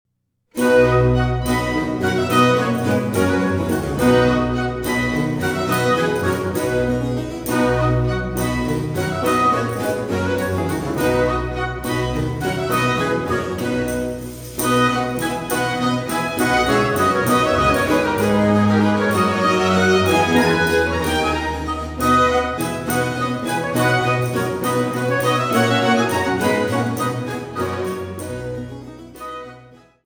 Instrumetalmusik für Hof, Kirche, Oper und Kammer
für zwei Oboen, Fagott, Steicher und Basso continuo